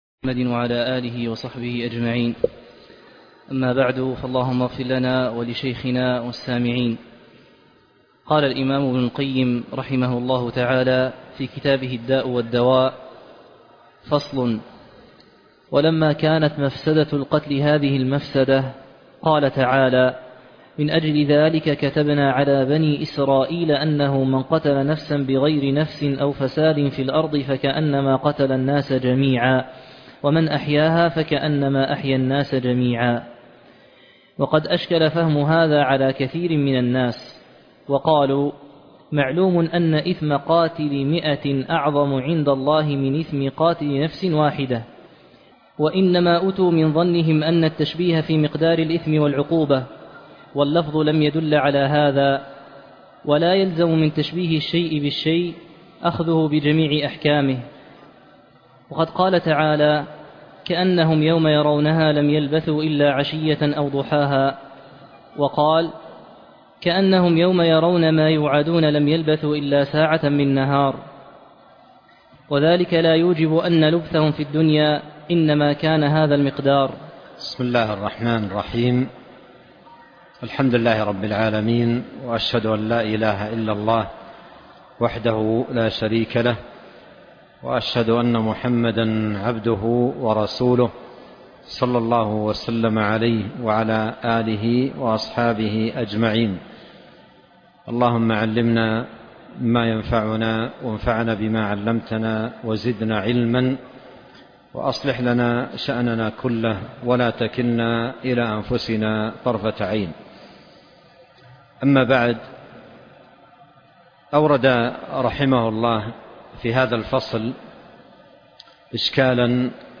كتاب الداء والدواء - فصل ولما كانت مفسدة القتل هذه المفسدة - الدرس 41